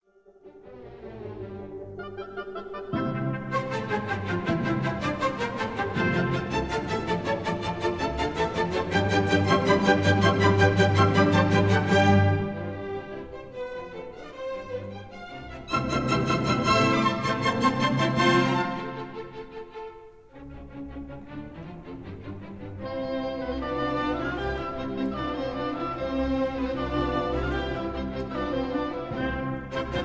in C major